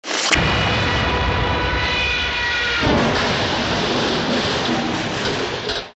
Звуки падения
Падение подобно самолету и удар